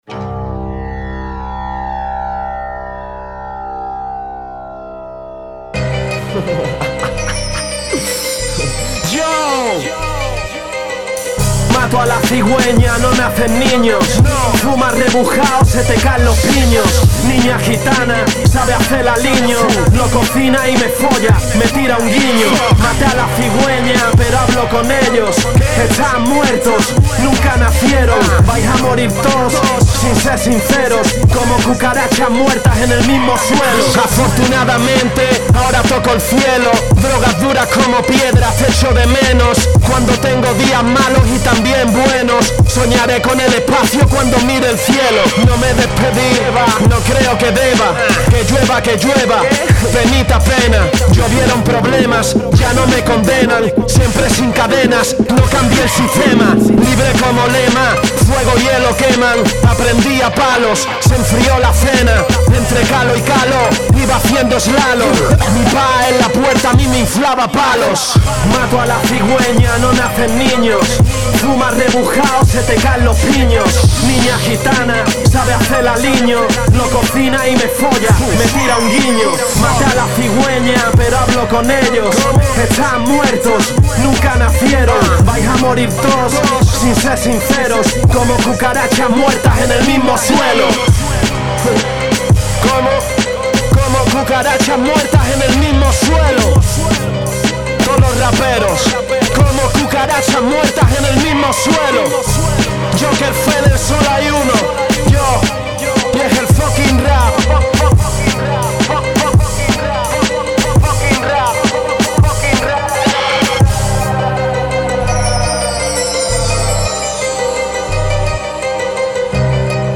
Está grabado, editado, mezclado y masterizado